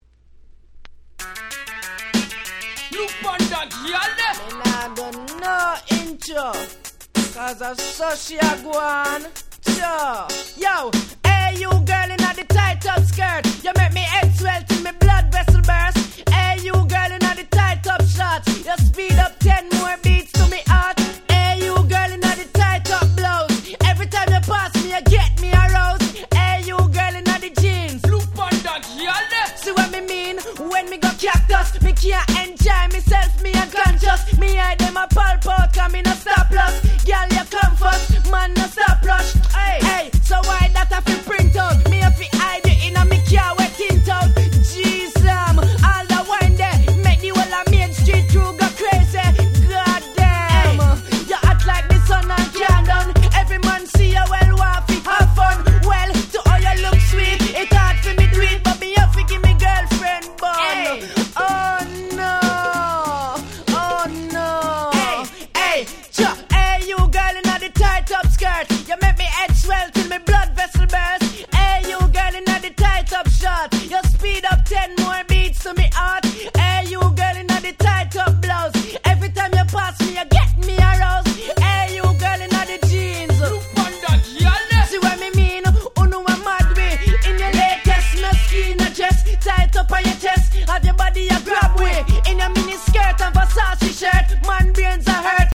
Nice Dancehall Reggaeコンピレーション！！